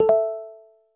Index of /phonetones/unzipped/BlackBerry/Priv/notifications